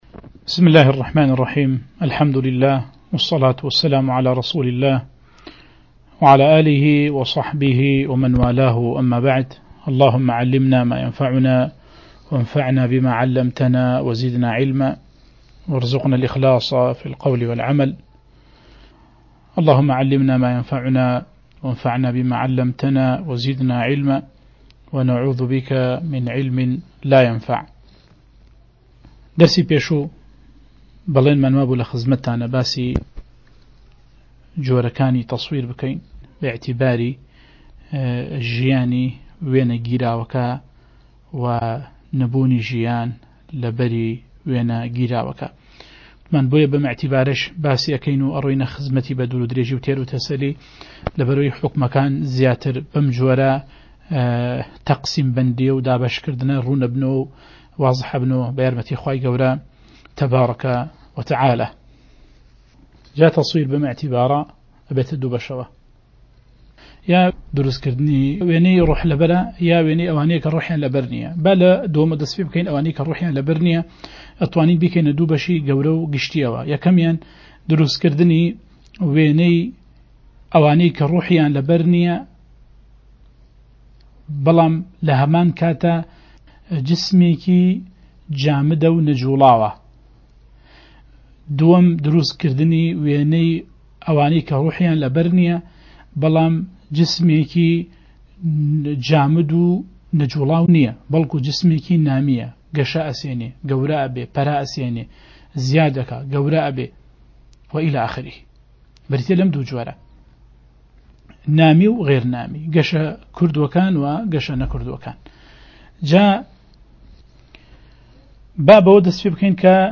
وانەی 2 - حوكمی وێنه‌ گرتن (التصوير) له‌ فيقهی ئيسلاميدا